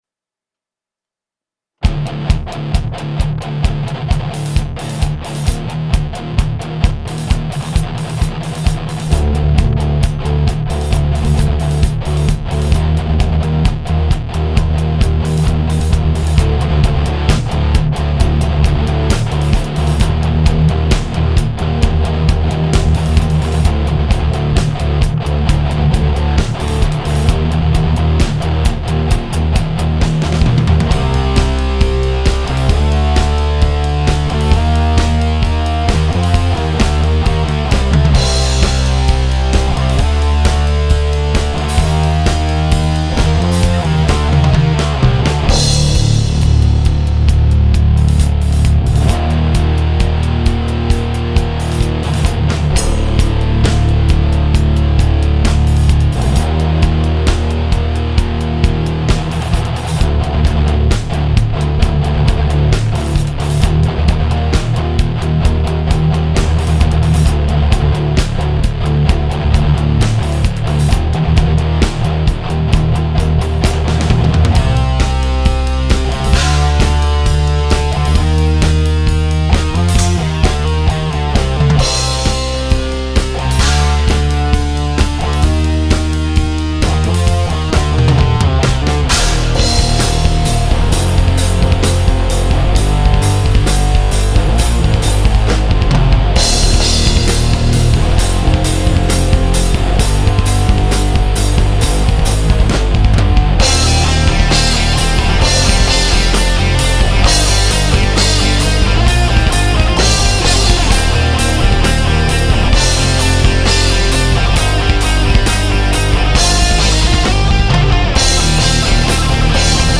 This one, I think, is cool, its gota sabbath meets machine head vibe to it! tell me what what could be better mix wise.